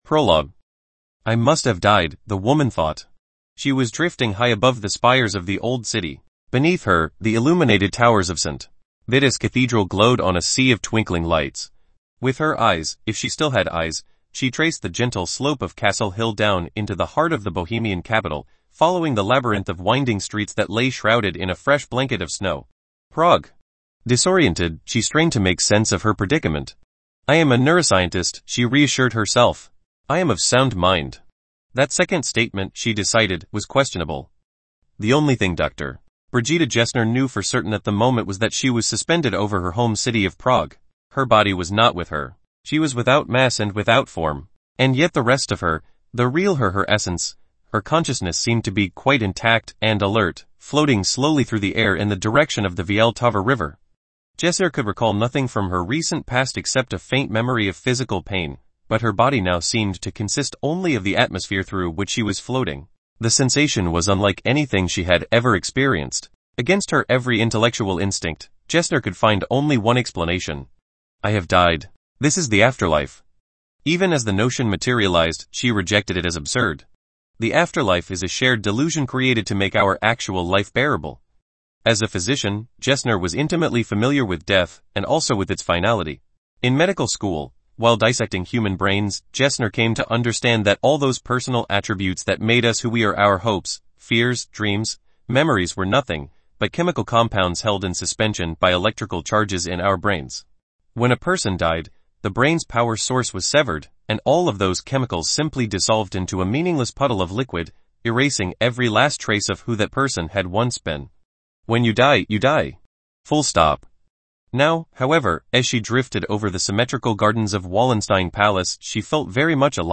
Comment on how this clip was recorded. English (home made) audio: